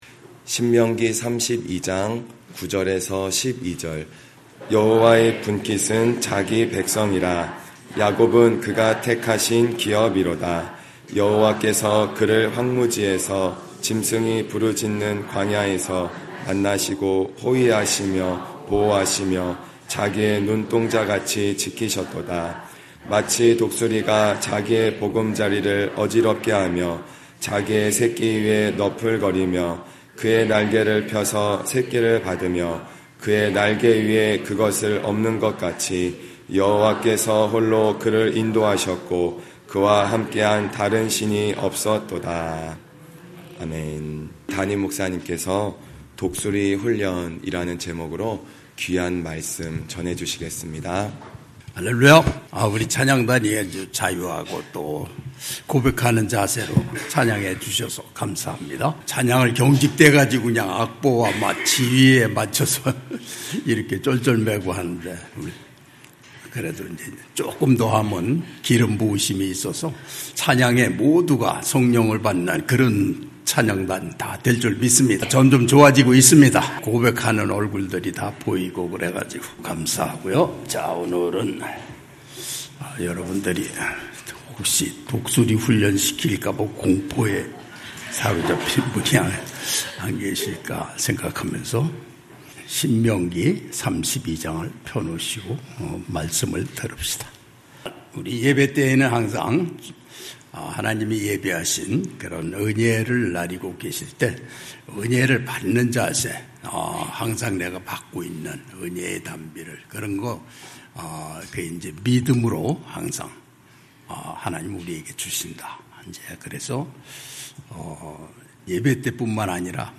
주일9시예배